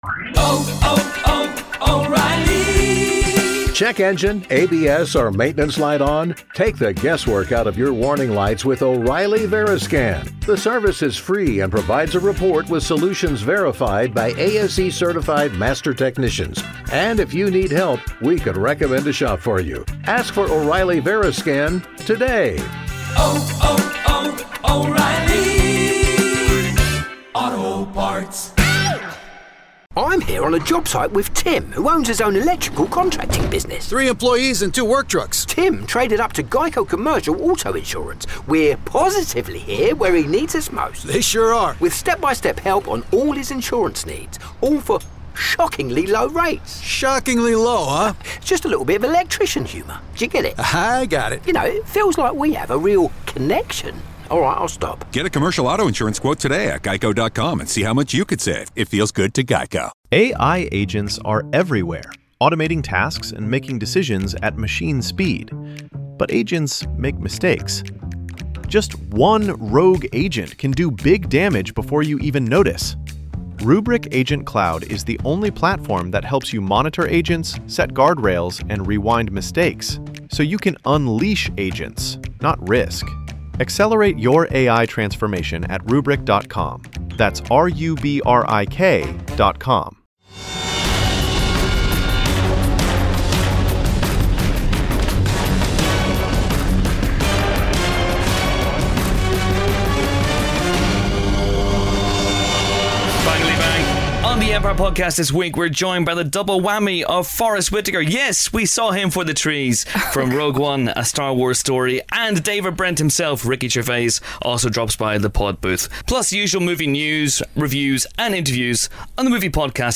On this week's pod: a famously softly-spoken man, and a famously less-softly-spoken man.